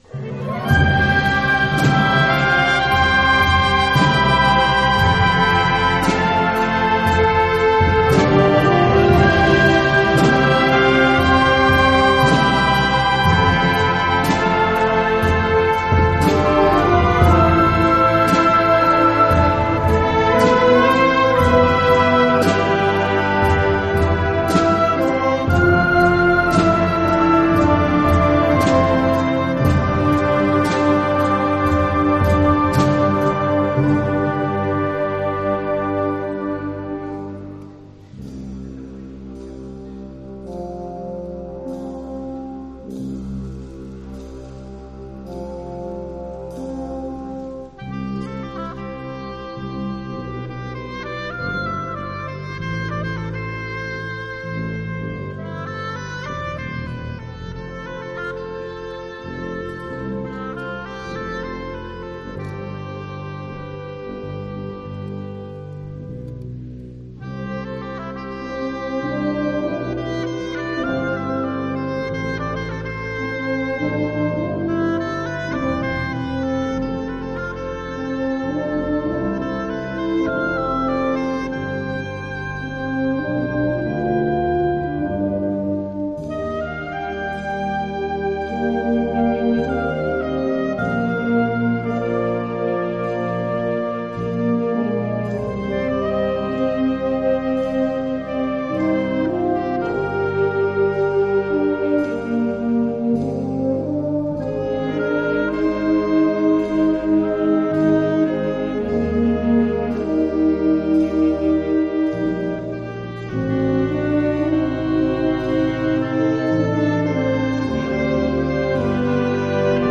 Genre musical : Classique
Oeuvre pour orchestre d’harmonie.